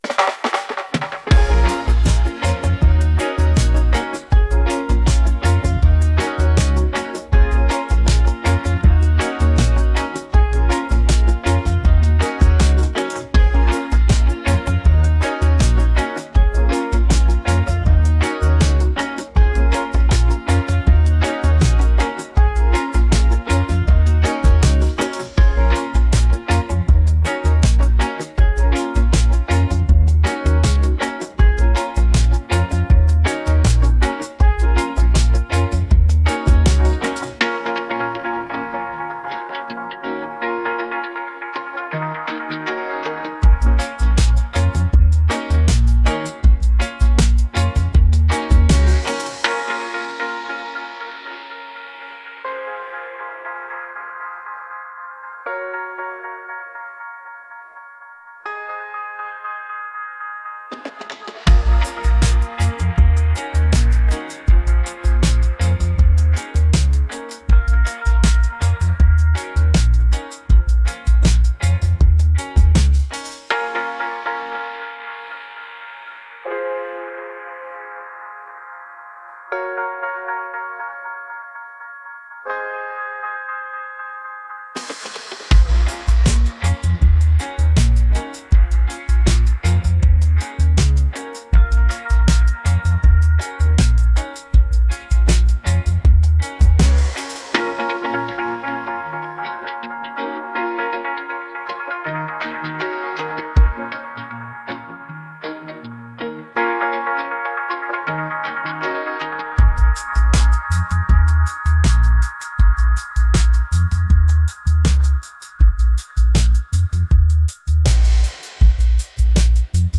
groovy | reggae